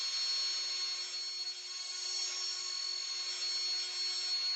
TurbineSpin.wav